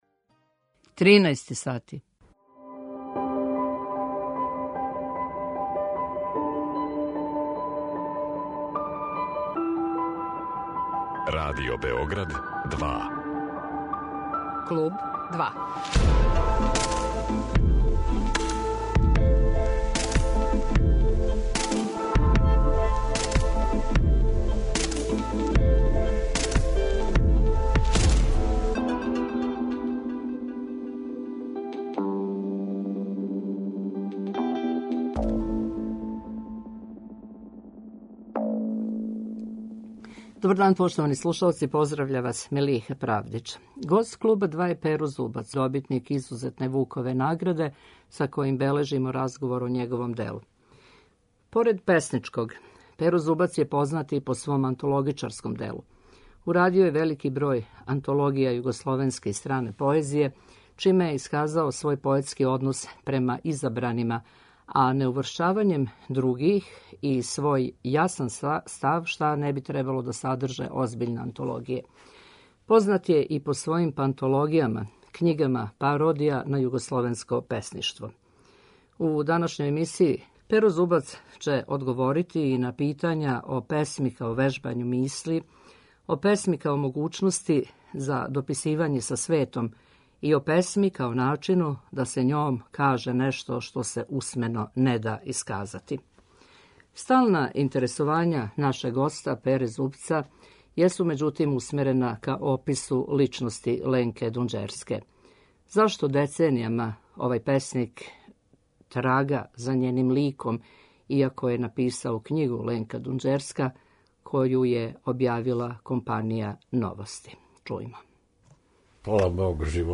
Гост Клуба 2 је књижевник Перо Зубац, који је носилац Изузетне Вукове награде.